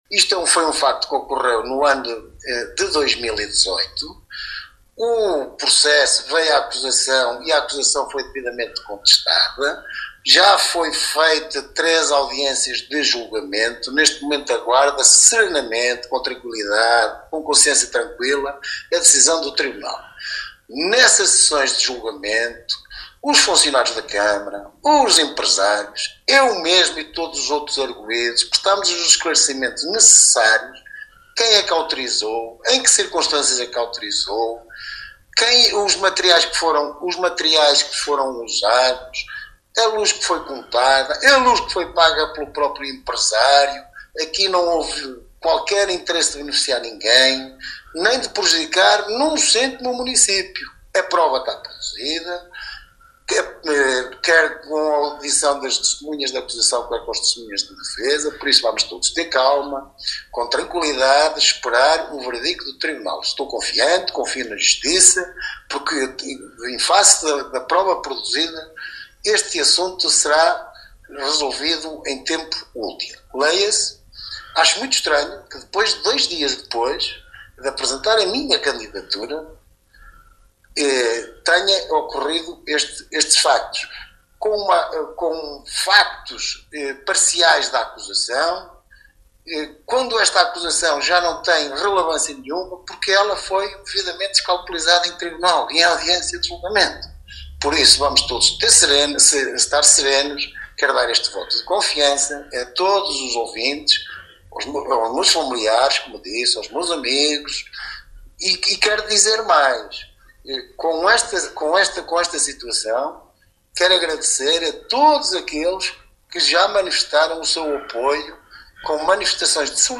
Depois da publicação em vários meios de comunicação social que dão conta de que José Morgado, ex-Autarca e agora candidato independente à Câmara Municipal de Vila Nova de Paiva nas próximas eleições Autárquicas 2025, sobre o facto de ter autorizado o uso da energia elétrica do estádio municipal para ser feita uma ligação elétrica para alimentar duas obras particulares que apesar de estarem licenciadas não cumpriam as normas urbanísticas, o atual Vice-presidente da CCDR-Centro, José Morgado, em declarações à Alive FM, refere estar tranquilo e confiante na justiça e reage com estranheza ao fato da noticia ter vindo a lume dois dias depois da apresentação da sua candidatura.